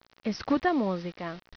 In this page, you can hear some brazilian portuguese words/phrases.